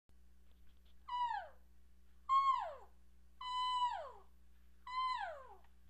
cowchirp
Cow Chirp Sounds Chirp Cow Elk make the same sounds as the calves. The difference is the lower pitch and longer duration than the calf sounds. The Chirp is a short sound, shorter than a mew.